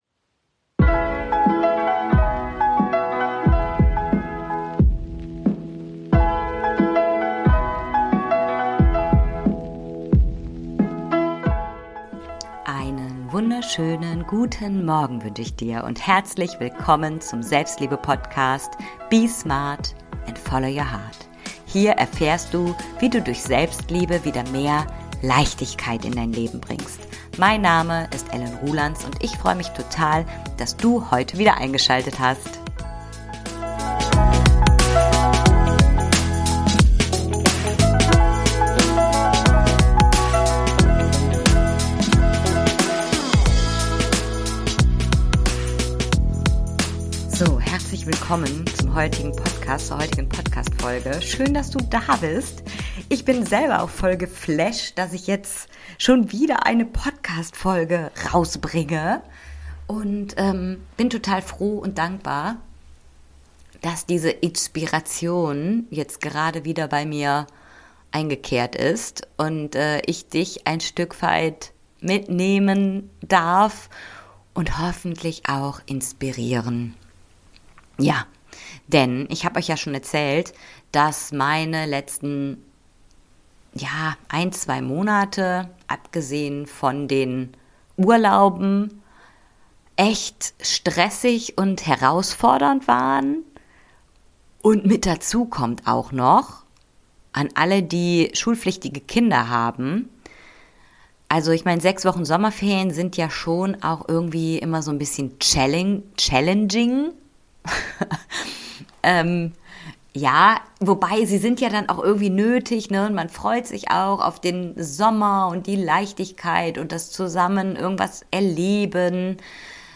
Herzlich Willkommen zu diesem sehr persönlichen Erkenntnis Monolog. Hier erfährst du wie ich mein persönliches Gleichgewicht aus Struktur und Freiraum lebe.